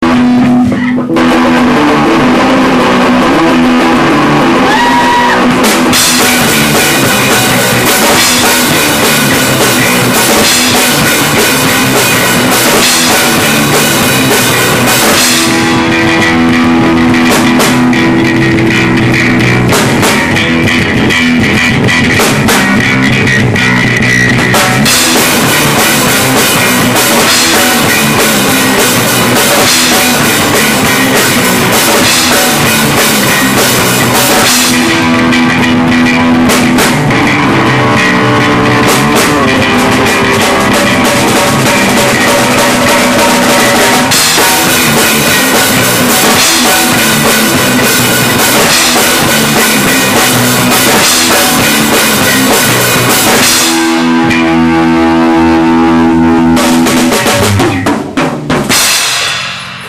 I don't know what kind of introduction would have been proper for our band section... we're a garage band from Palm Harbor. we play rock.
All the material available below was recorded using a Hi8 sony camcorder. the a double ended headphone cable was run to my mic input and sound recorder was used to record from the tape.